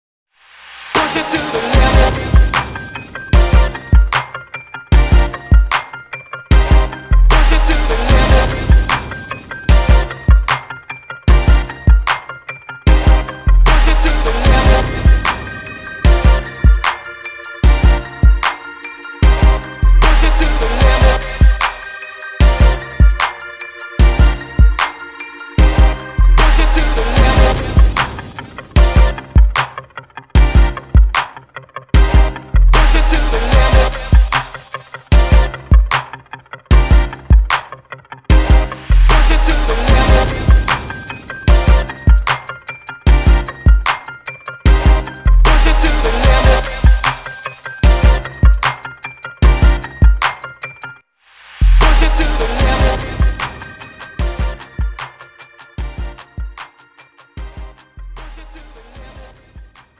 (팝송) MR 반주입니다. Premium MR로, 프로 무대와 웨딩 이벤트에 어울리는 고급 반주입니다.